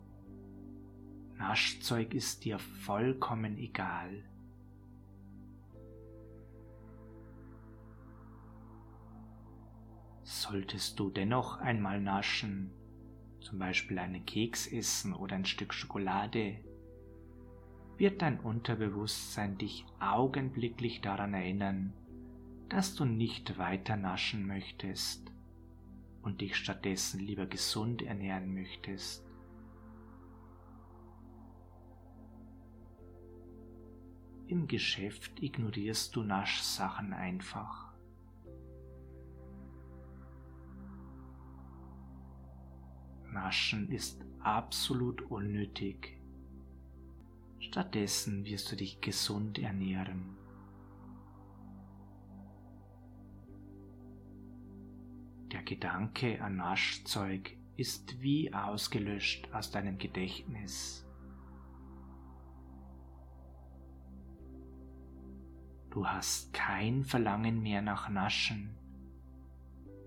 Verzichten Sie mit dieser geführten Hypnose in Zukunft ganz leicht auf unnötiges Naschzeug.